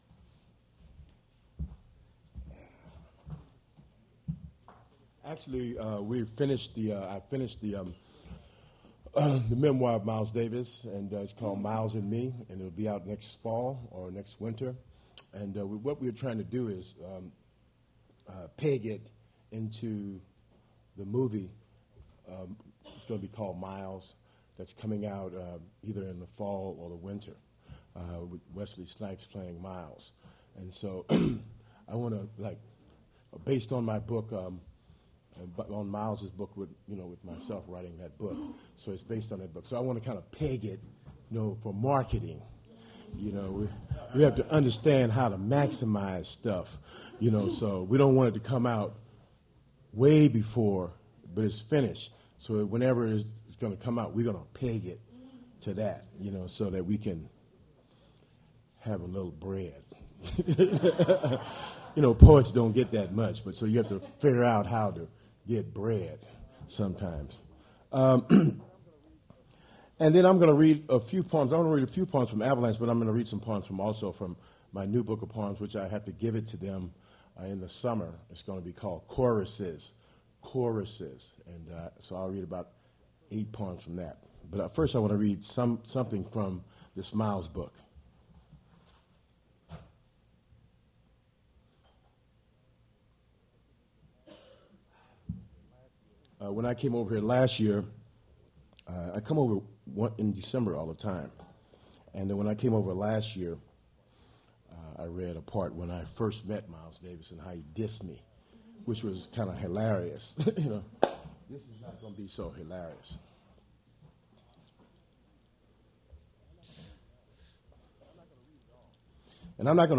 Poetry reading featuring Quincy Troupe
Attributes Attribute Name Values Description Quincy Troupe poetry reading at Duff's Restaurant.
mp3 edited access file was created from unedited access file which was sourced from preservation WAV file that was generated from original audio cassette.